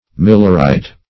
Millerite \Mil"ler*ite\, n.